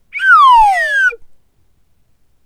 slidewhistleDown.wav